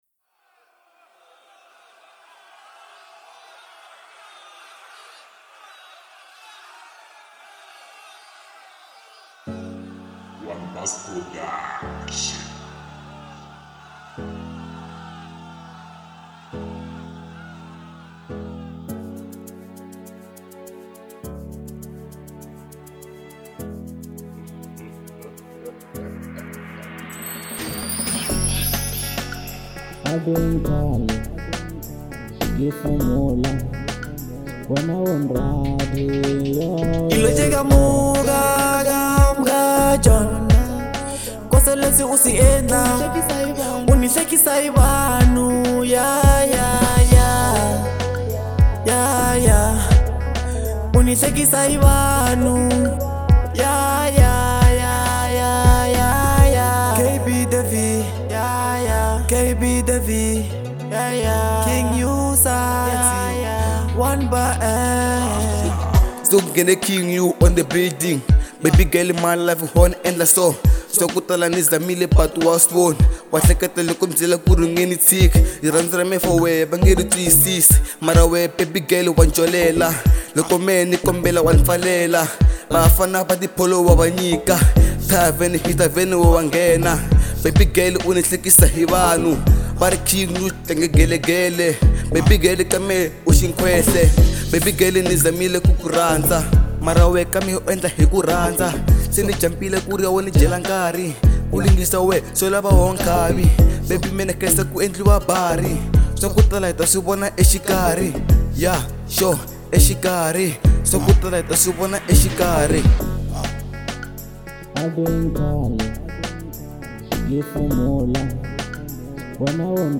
Genre : Local House